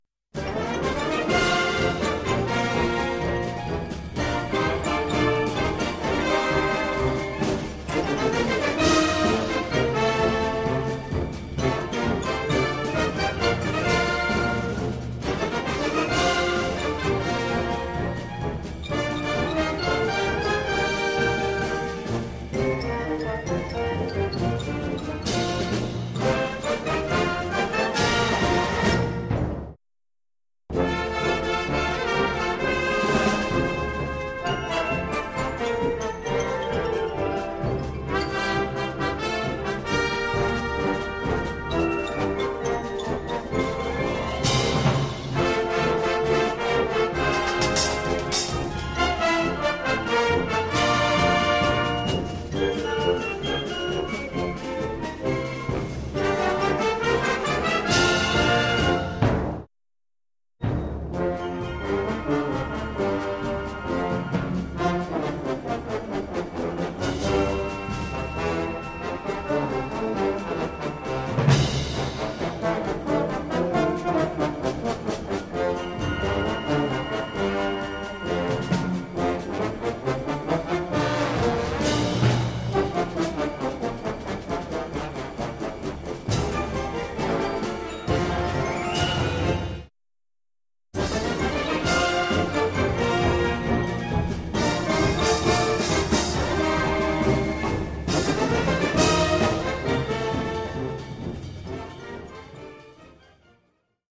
Collection : Harmonie (Feria)
Oeuvre pour harmonie, classe
d’orchestre ou banda.